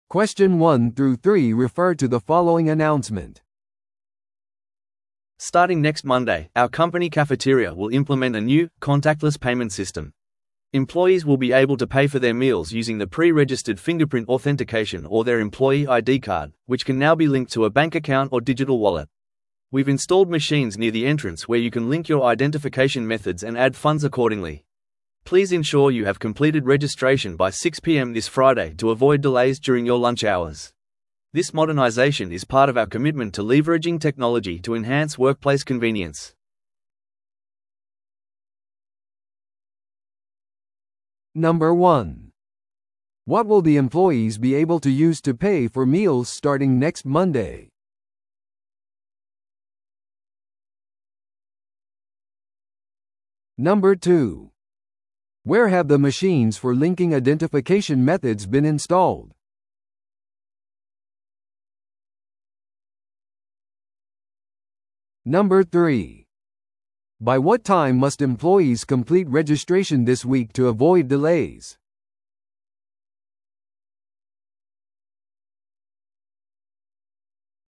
TOEICⓇ対策 Part 4｜社員食堂の支払システム – 音声付き No.025